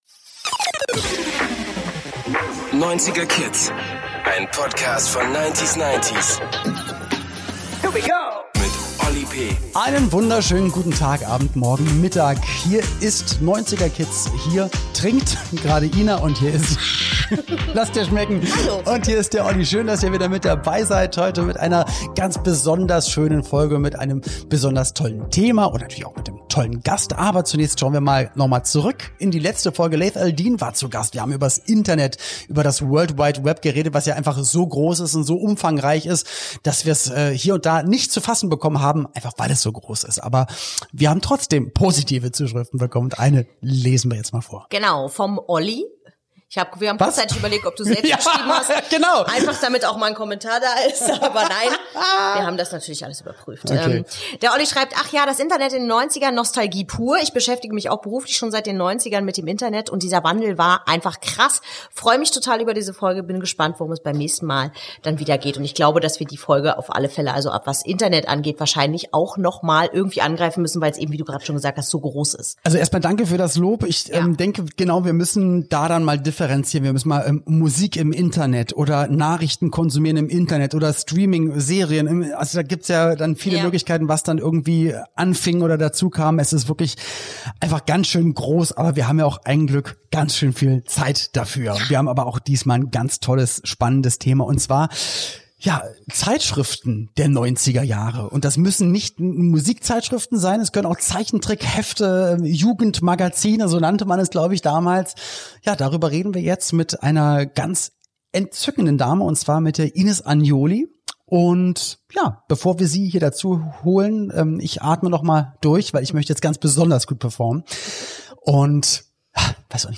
Welche Zeitschrift Ines Anioli in den 90er gern gelesen hat und warum die Bravo uns allen beim Erwachsenwerden geholfen hat, erzählt sie Oli.P im Podcastinterview.